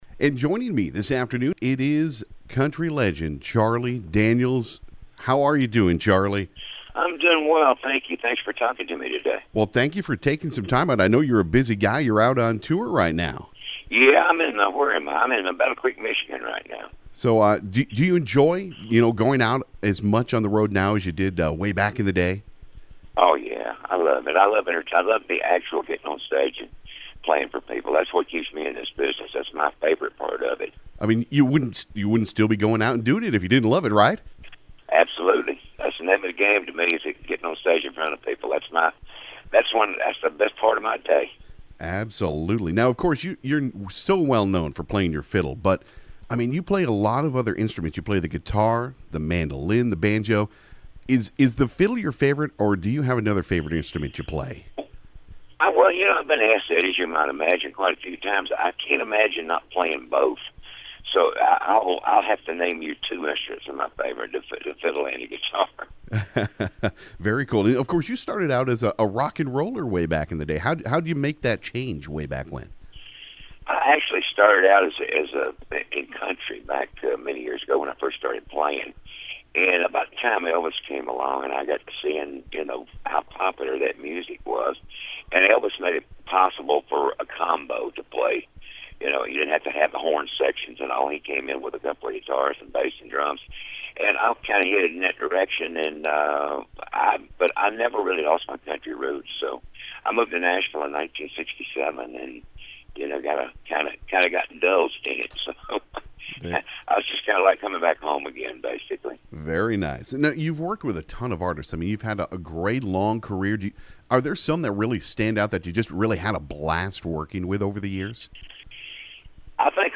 Charlie Daniels Interview [From April 2016]